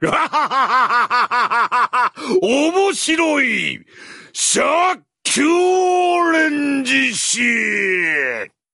HAHAHAHAHA omoshiroi sound button getting viral on social media and the internet Here is the free Sound effect for HAHAHAHAHA omoshiroi that you can download